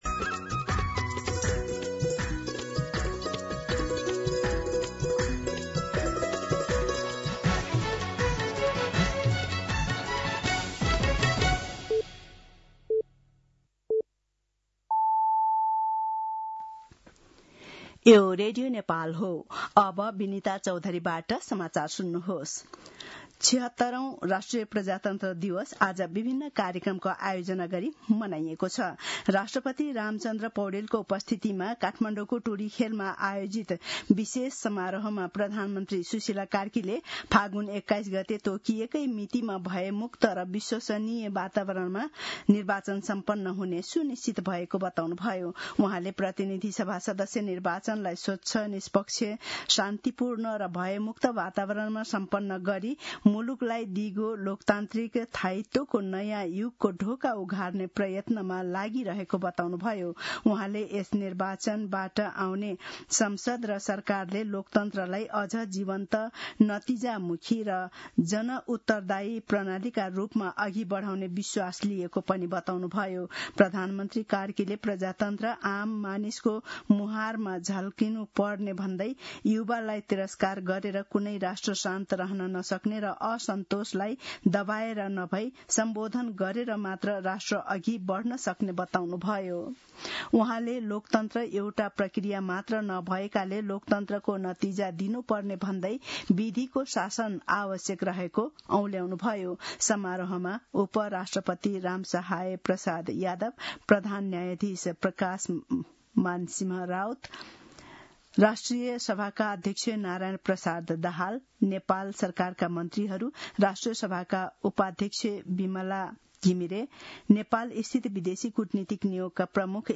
दिउँसो १ बजेको नेपाली समाचार : ७ फागुन , २०८२